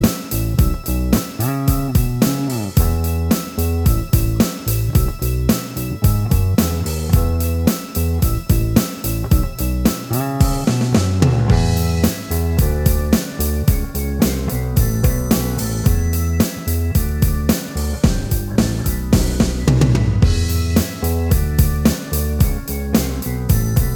Minus Lead Guitar Indie / Alternative 3:07 Buy £1.50